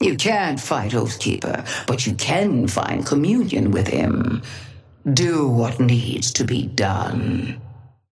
Patron_female_ally_ghost_oathkeeper_5g_start_03.mp3